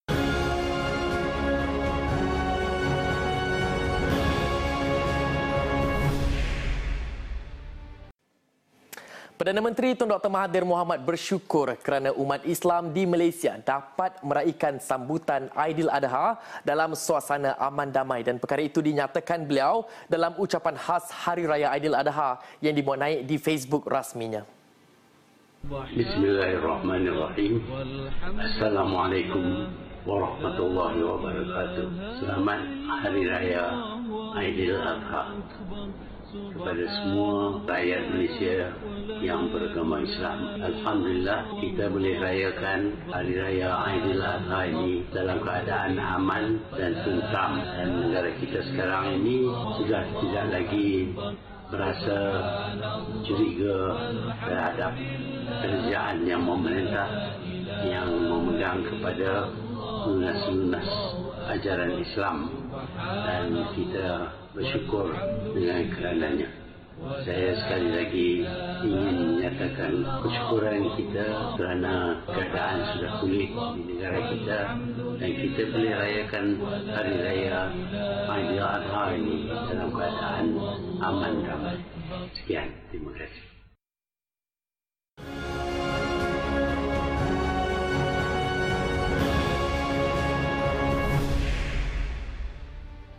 Ucapan khas Aidiladha PM
Perkara itu dinyatakan beliau dalam ucapan khas Hari Raya Aidiladha yang dimuatnaik di Facebook rasminya.